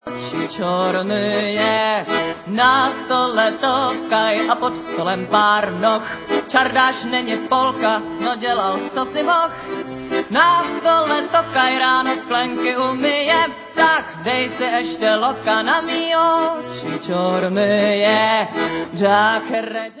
Záznam koncertu